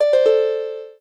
lyre_d1c1a.ogg